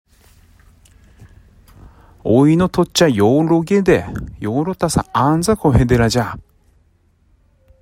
津軽方言：古代の発音
津軽方言のを聞くと、古代日本語の音声の一部が、現代の津軽方言に受け継がれていることが分かります。